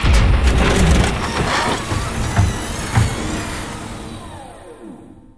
cargorepair.wav